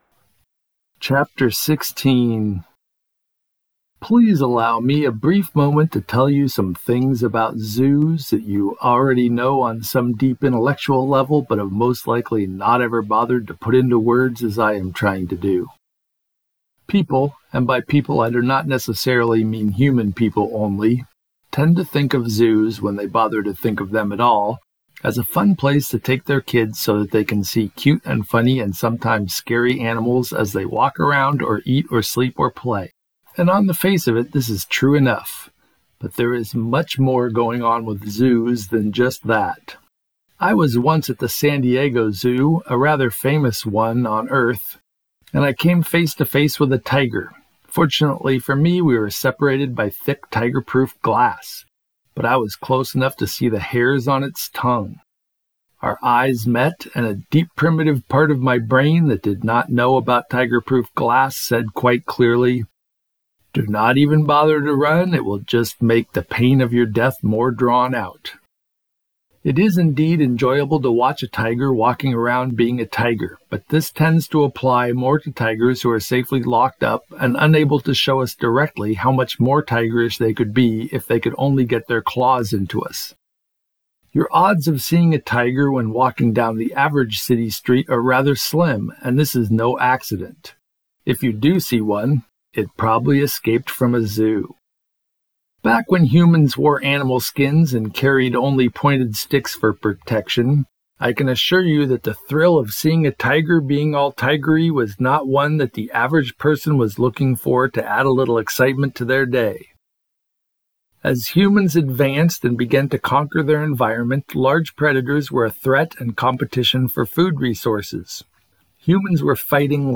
This is a free audio book chapter, read by me, that explains why almost all races in all the universes end up inventing zoos of one sort or another.